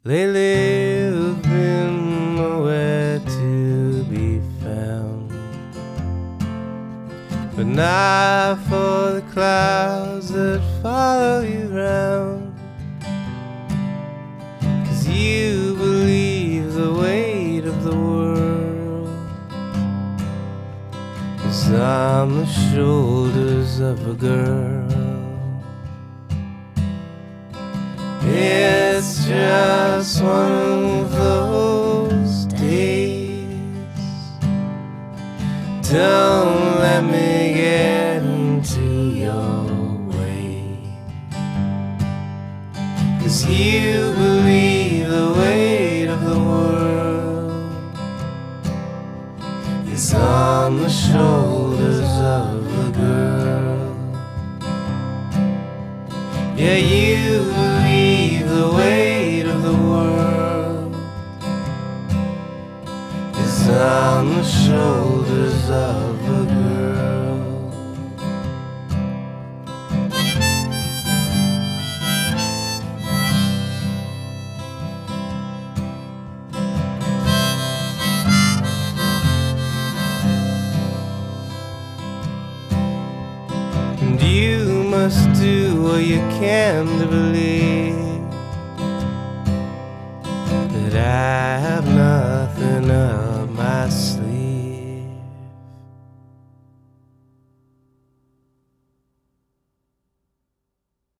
gravelly delivery
just channel a sordid sounding sadness.